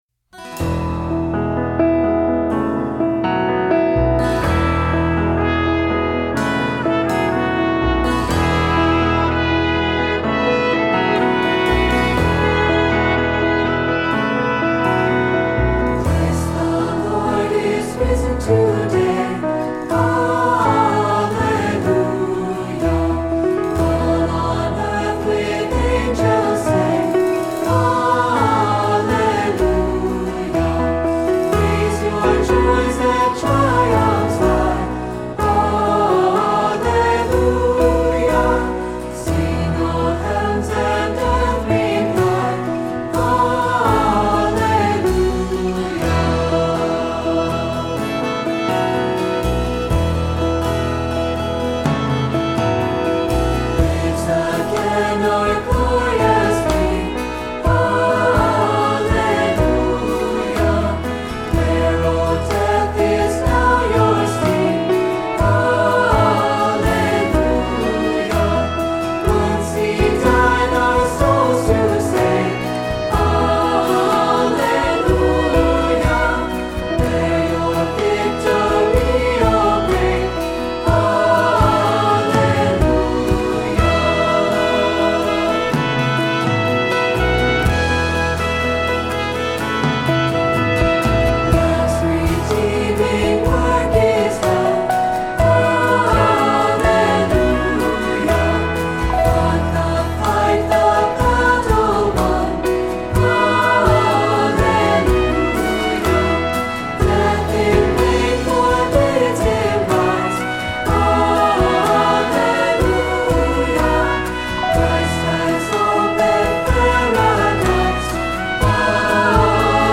Accompaniment:      Piano
Music Category:      Christian
adds a modern accompaniment